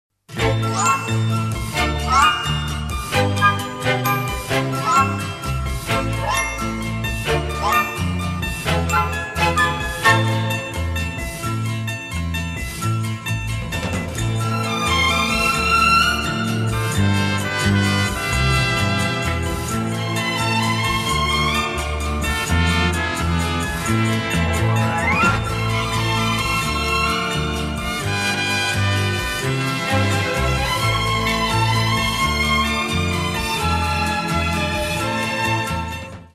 • Качество: 320, Stereo
OST